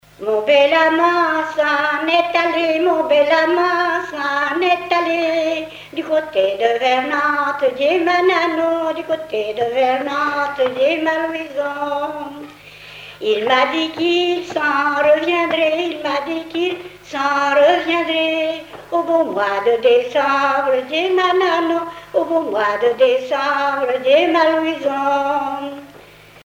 Chansons et contes traditionnels
Pièce musicale éditée